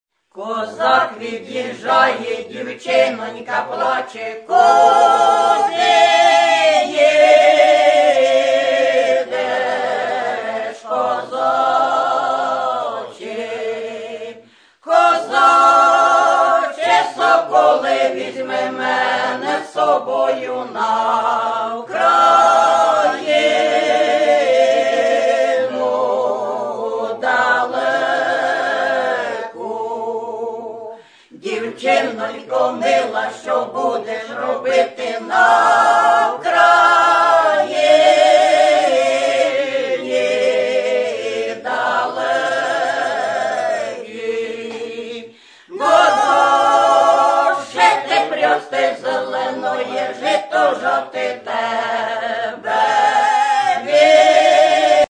Authentic Performing